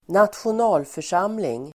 Uttal: [²nat:sjon'a:lför_sam:ling]